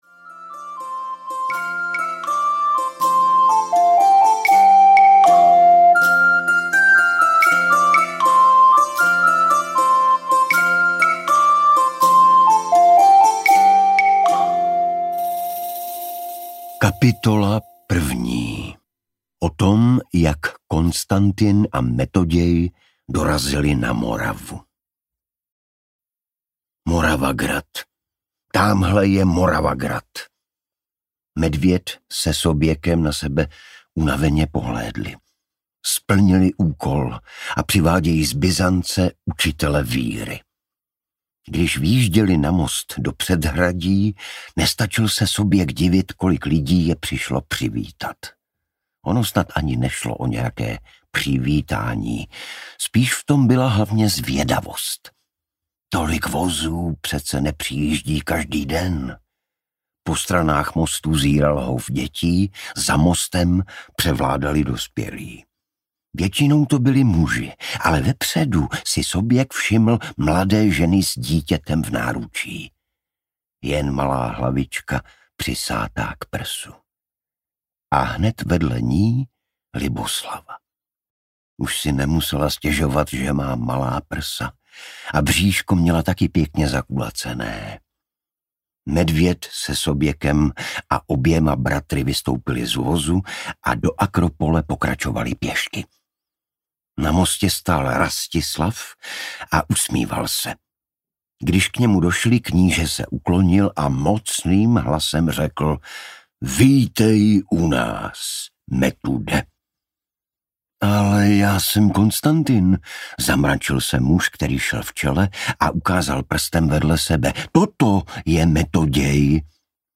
Ukázka z knihy
• InterpretIgor Bareš